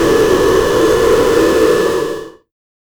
make an sfx about other exclamations of awe and excitement
make-an-sfx-about-other-2puwudut.wav